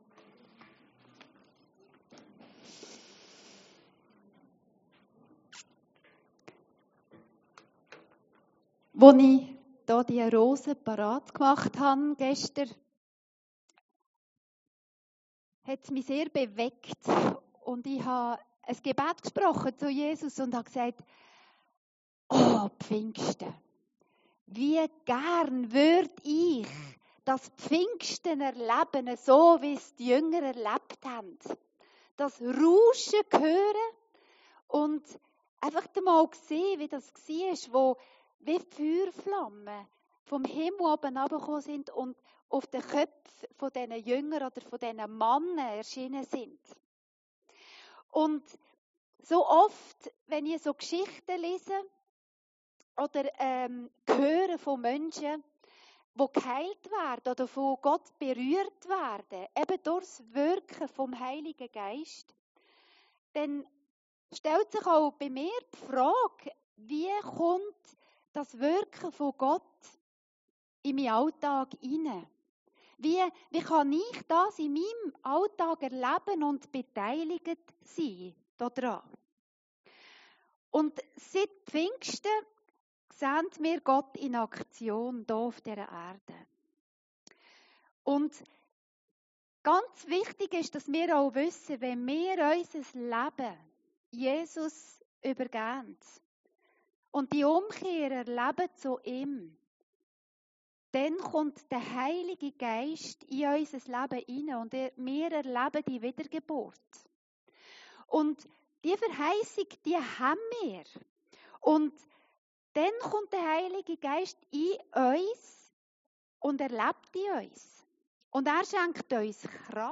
Predigten Heilsarmee Aargau Süd – Den Heiligen Geist im Alltag erleben